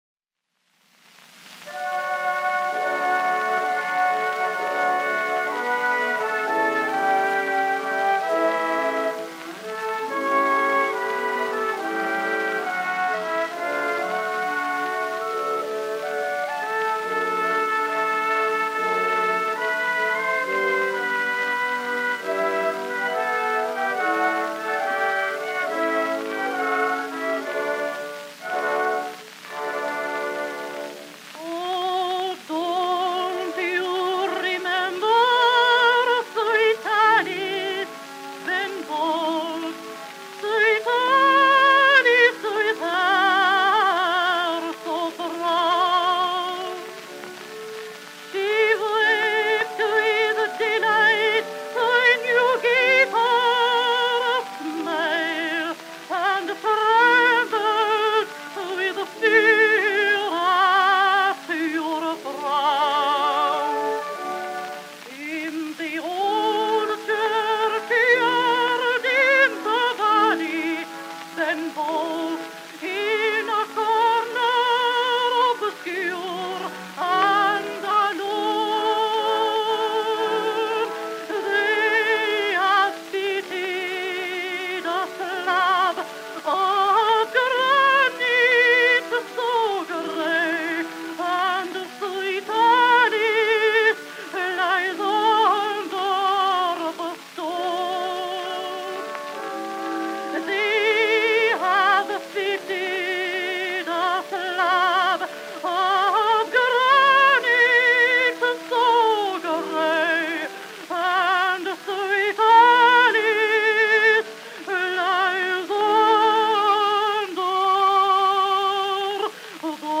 This version of the song was recorded in 1912 and featured vocals by Eleonora de Cisneros. At the time, she was one of the most celebrated singers in the world.
ben-bolt-oh-dont-you-remember-sweet-alice-ben-bolt_-eleonora-de-cisneros.mp3